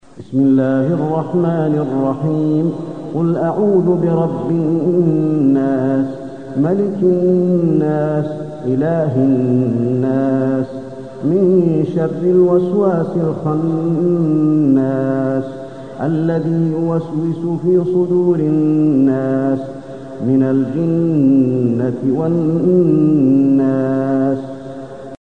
المكان: المسجد النبوي الناس